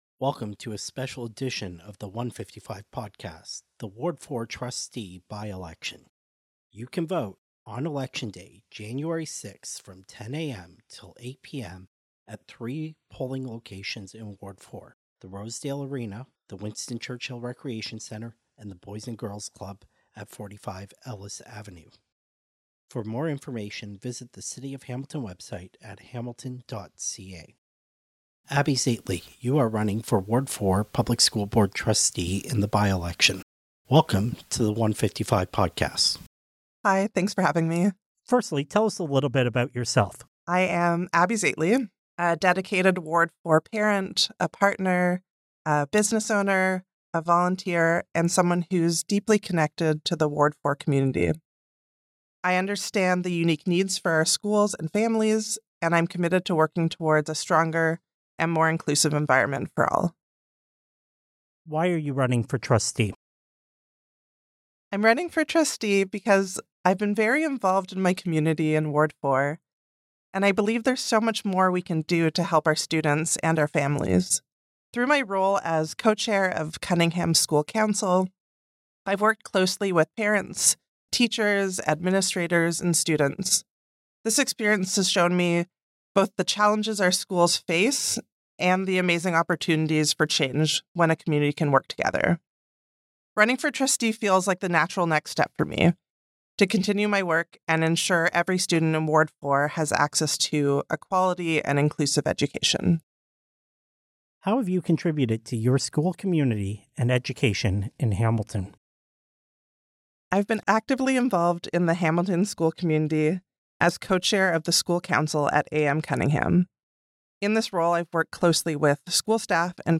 Interviews and discussions with Hamilton Ontario Canada's newsmakers, community leaders, thinkers, and academics about the issues that inform Hamilton's civic affairs.